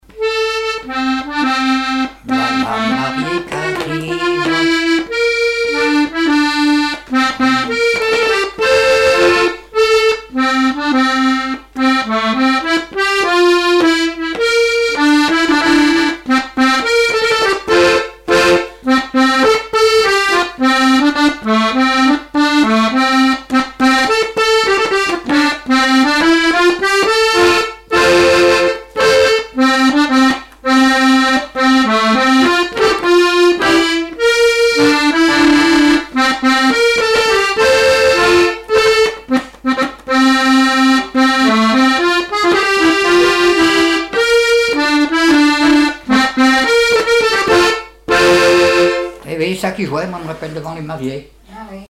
marche de cortège de noce
accordéon chromatique
Pièce musicale inédite